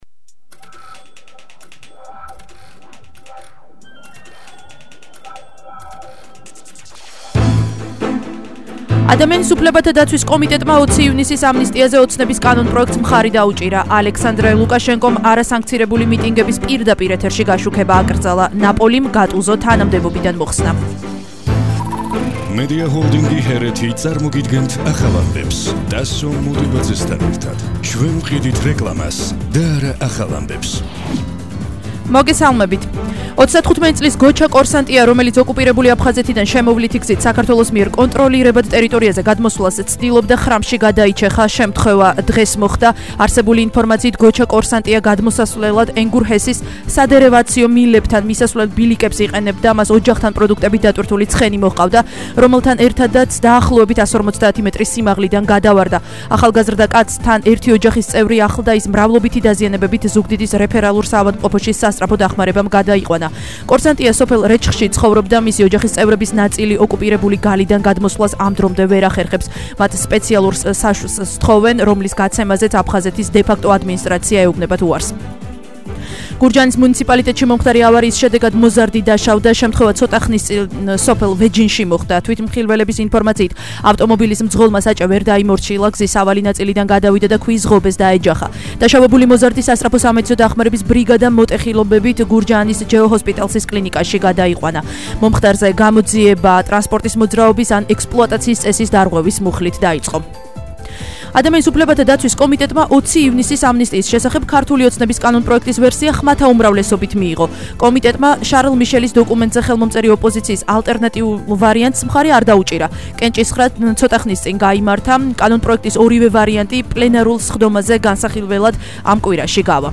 ახალი ამბები 17:00 საათზე –24/05/21 - HeretiFM